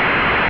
Jet.SND